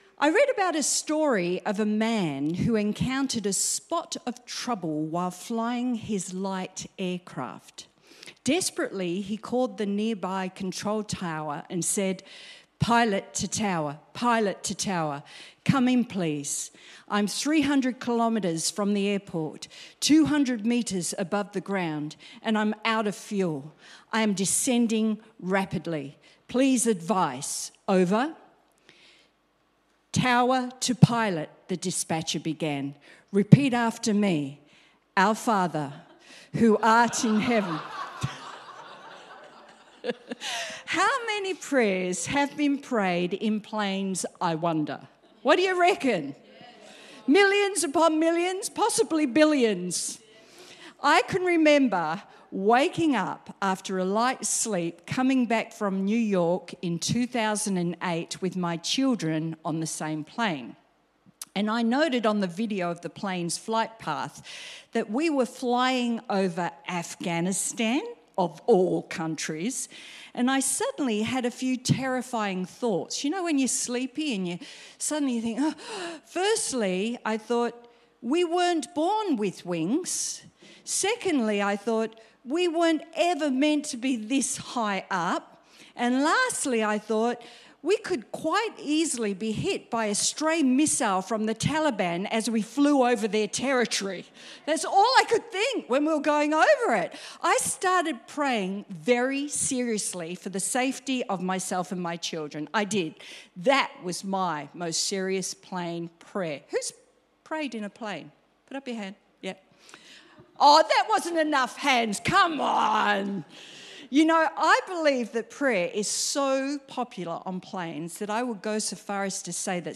Sermon Transcript: I read about a story of a man who encountered a spot of trouble while flying his light aircraft.